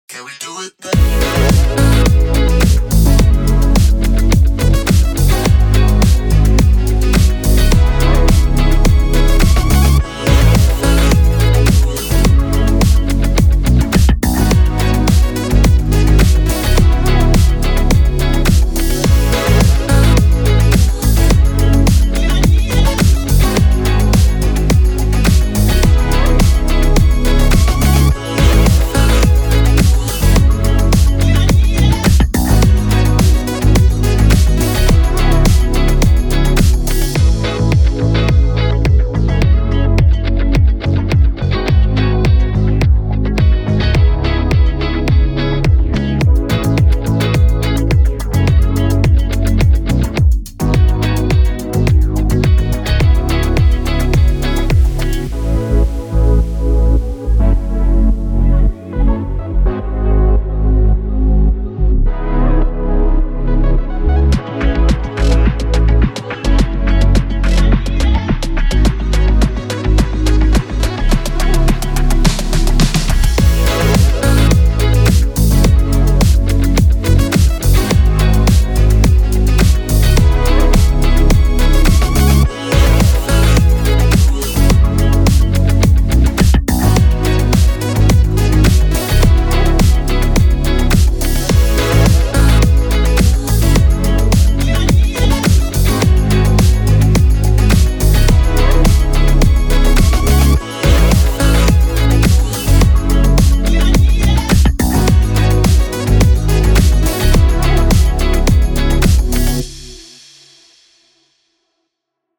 Latino
Salsa, reguetón, bachata y fusiones modernas con mucho sabor y buena energía.